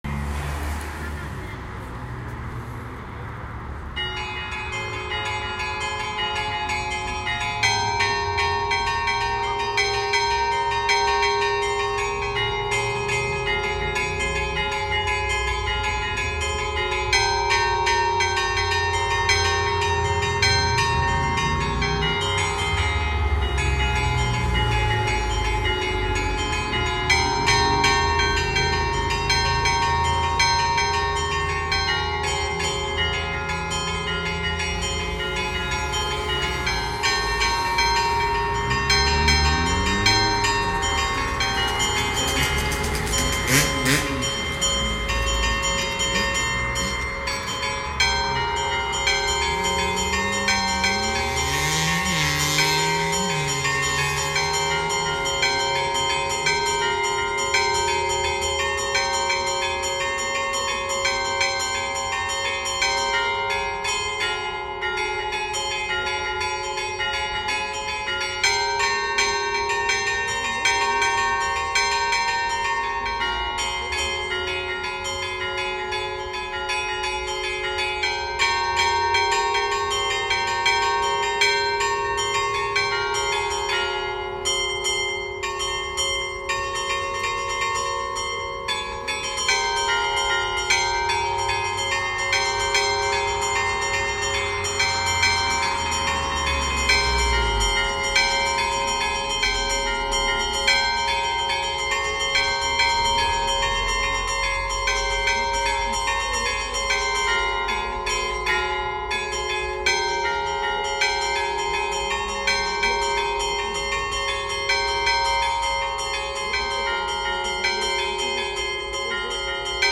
LE CINQUE CAMPANE DELLA CHIESETTA DI S. ANTONIO  IN LOCALITÀ TREDES
Attualmente è stata ripristinata la tastiera con i relativi tiranti per potere suonare in “allegrezza” e diffondere motivetti gioiosi  e festivi.
campane-s.-Antonio1.m4a